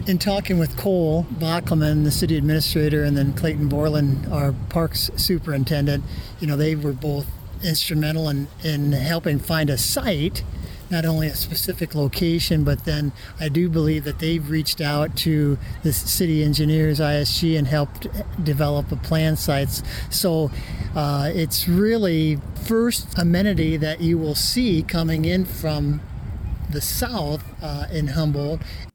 Humboldt Mayor Dan Scholl says the memorial will be a nice addition to honor veterans on the south side of town.